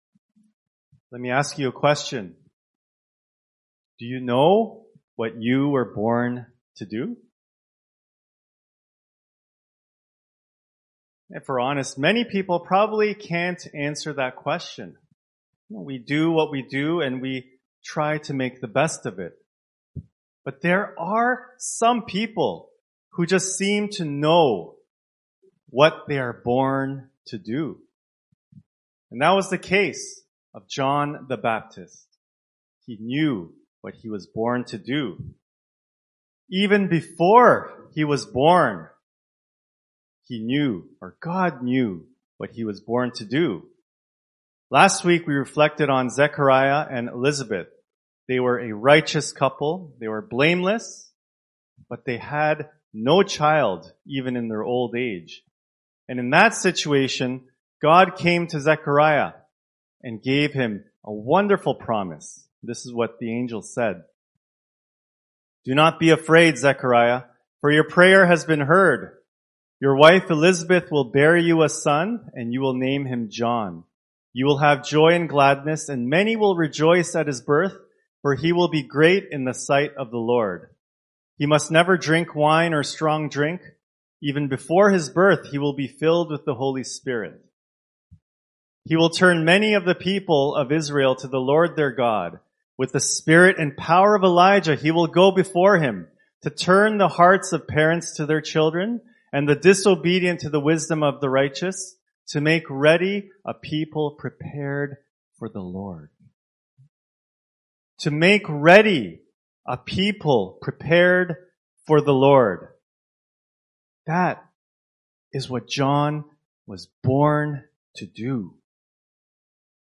St Timothy Presbyterian Church in Toronto: Sermons